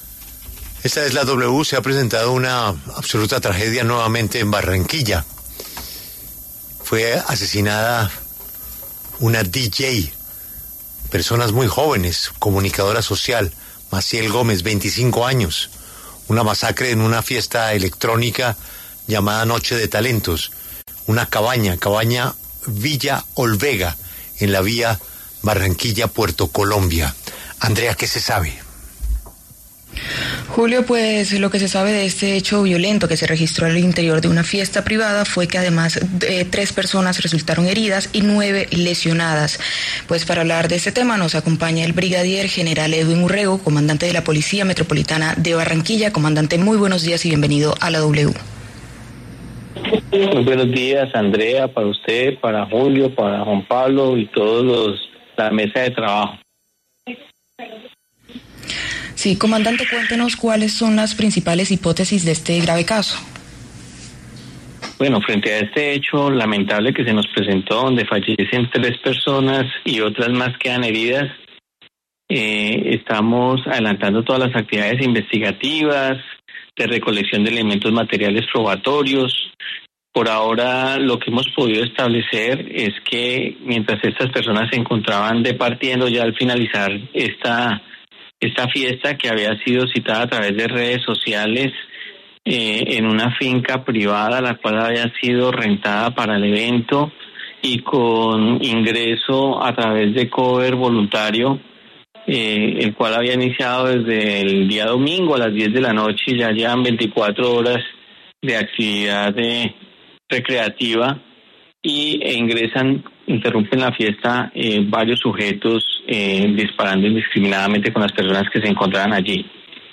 En conversación con La W, el brigadier general Edwin Urrego, comandante de la Policía Metropolitana de Barranquilla, habló sobre el hecho violento en el que tres personas murieron y nueve resultaron lesionadas en una fiesta privada en Puerto Colombia, Atlántico.